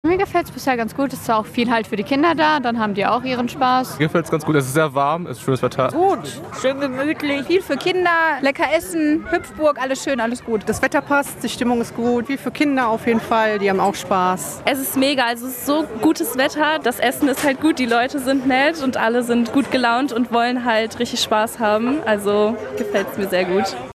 Sommerfest im Hameckepark
Anzeige Besucher Das Sommerfest hat den Besuchern wieder gut gefallen play_circle Abspielen download Anzeige